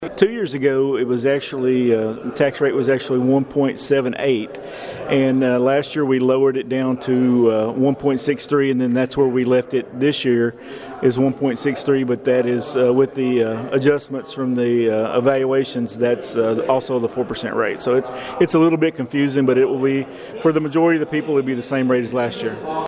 The Princeton City Council met in regular session at 5:00 pm on Monday afternoon.